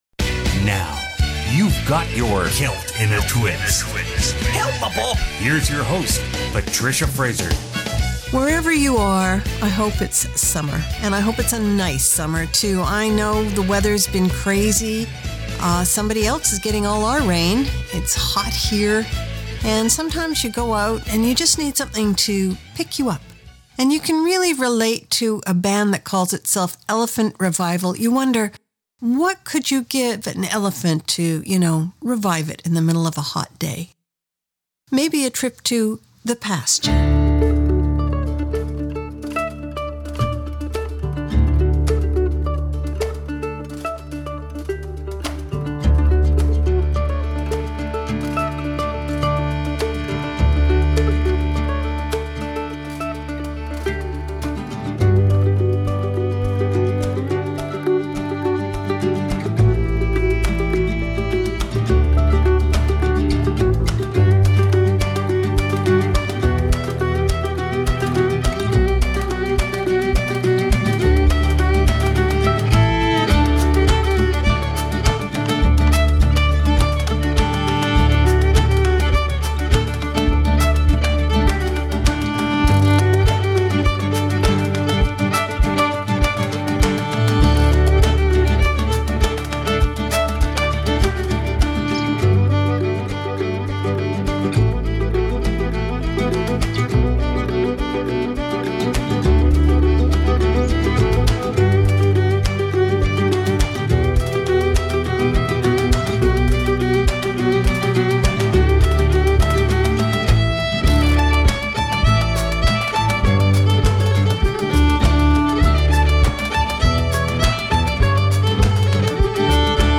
Canada's Contemporary Celtic Radio Hour
14 Celtic road tunes!